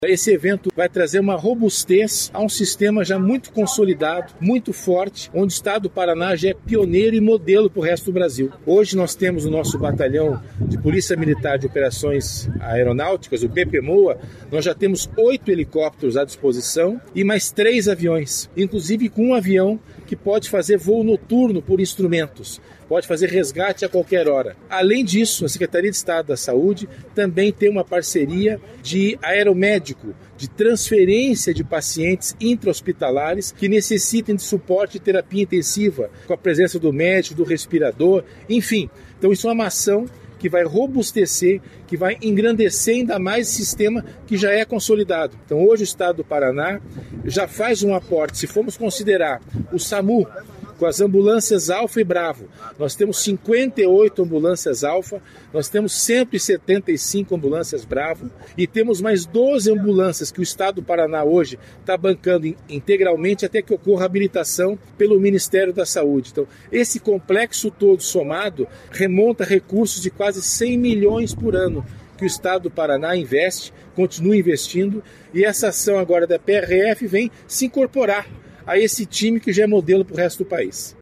Sonora do diretor-geral da Sesa, César Neves, sobre novo helicóptero da PRF que ficará à disposição para resgates aeromédicos na RMC e Litoral do Paraná